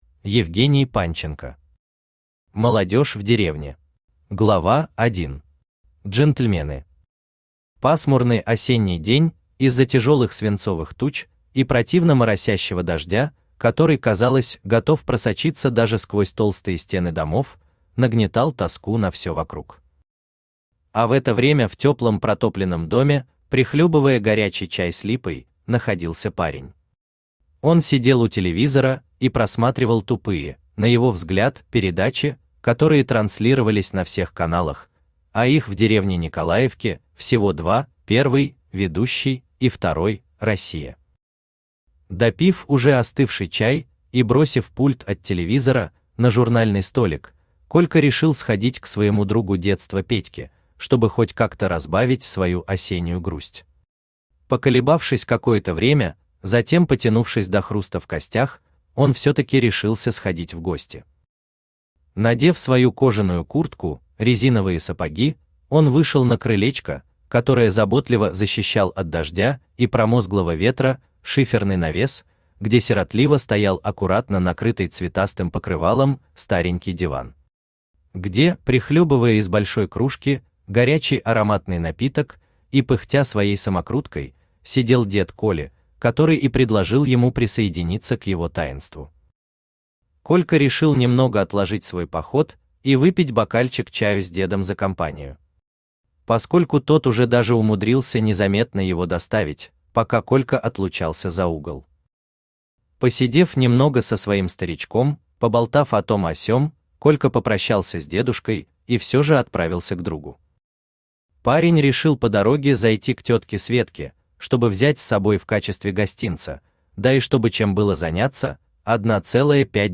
Аудиокнига Молодежь в деревне | Библиотека аудиокниг
Aудиокнига Молодежь в деревне Автор Евгений Александрович Панченко Читает аудиокнигу Авточтец ЛитРес.